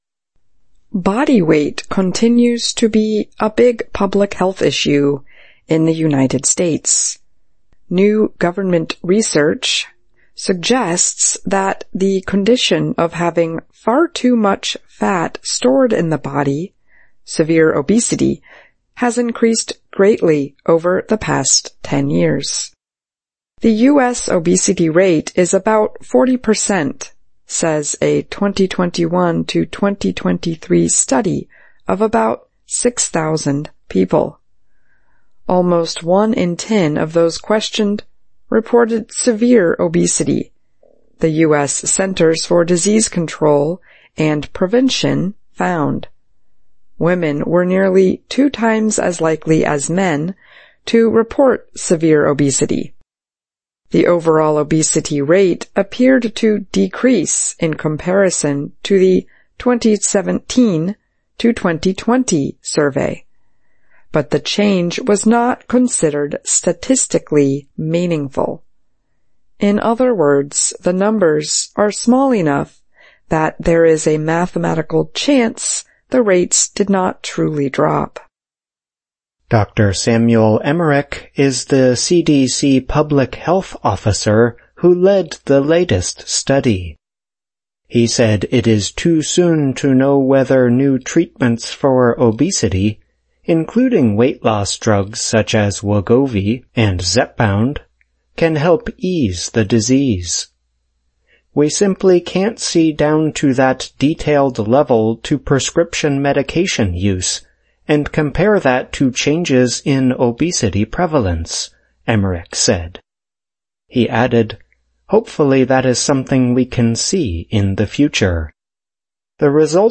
VOA慢速英语逐行复读精听提高英语听力水平